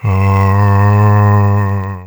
c_zombim4_atk1.wav